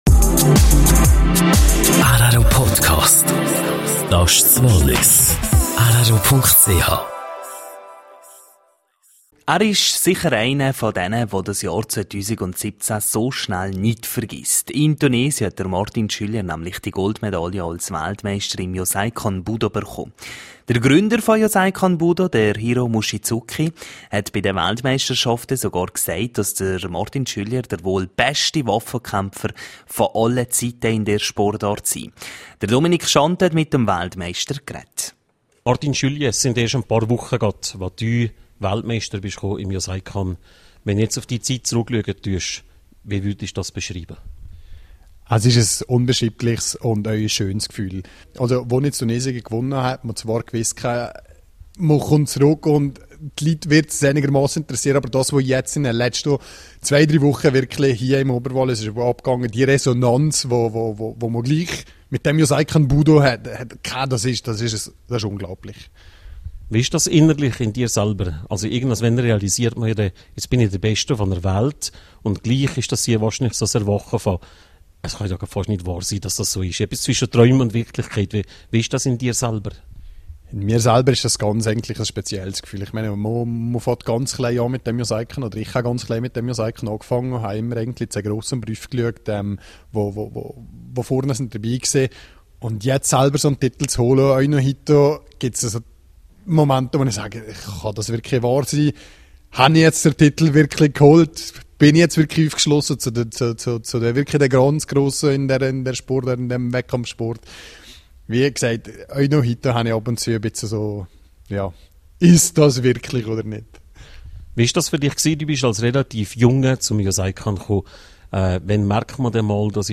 Person des Jahres 2017: Interview